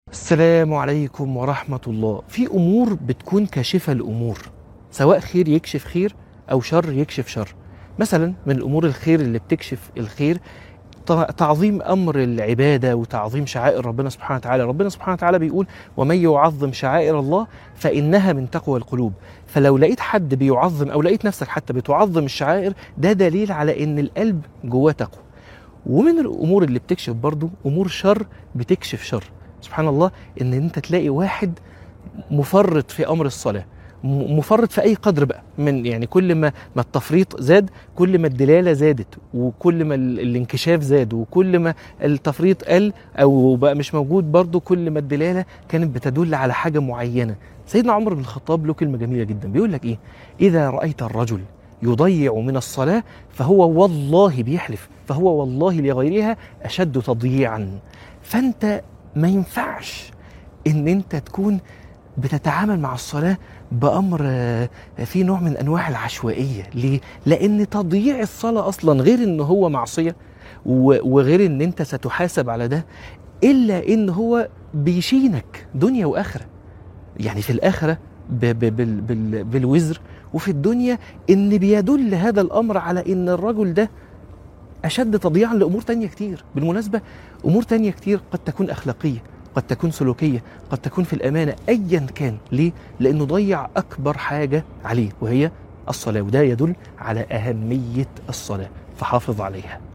من الحرم